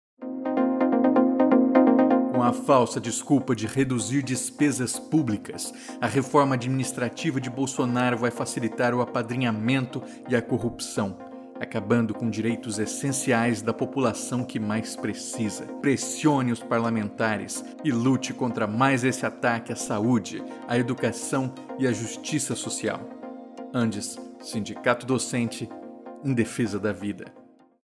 Também estamos entrando com inserções de áudio em redes sociais, com os spots abaixo, realizados pela equipe de comunicação das seções gaúchas do ANDES-SN, que reúne Adufpel, Aprofurg, Sedufsm, Sesunipampa, Seção da UFRGS e SindoIF, com apoio da Regional RS.
Spot3_PEC32-1.mp3